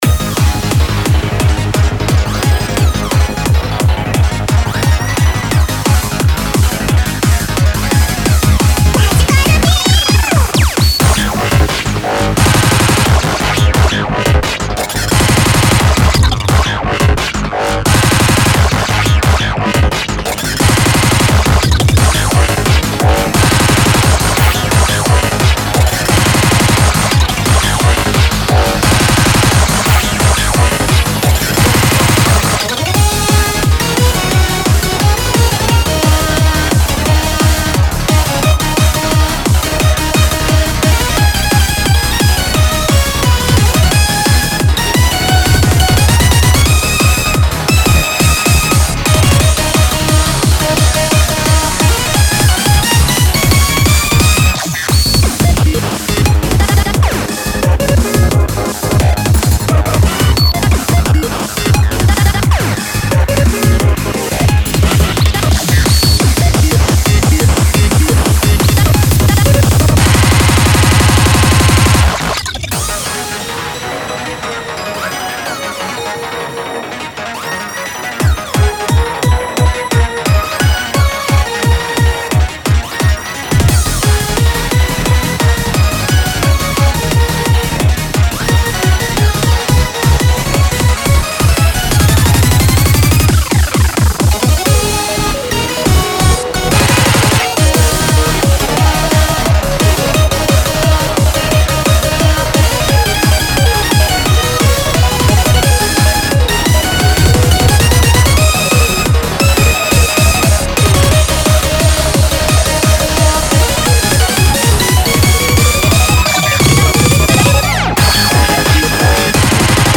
BPM175
Audio QualityPerfect (High Quality)
Genre: VERTICAL MAGIC